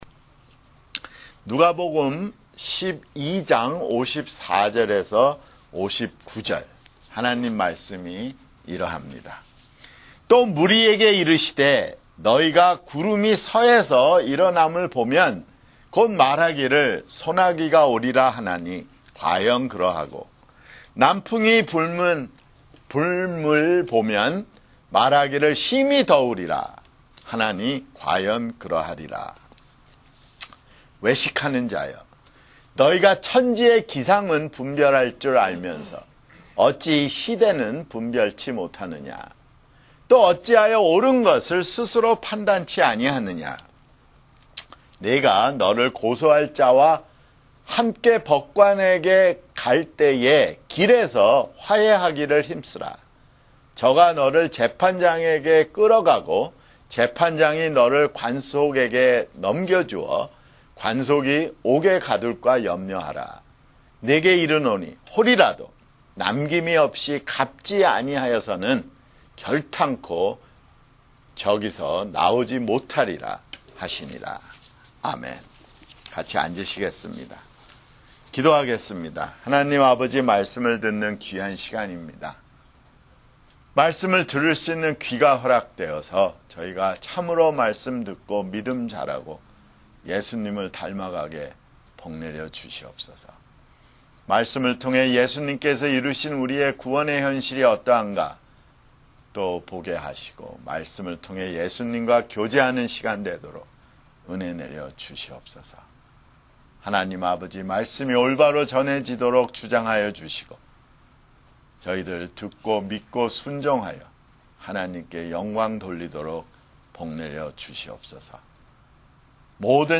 [주일설교] 누가복음 (92)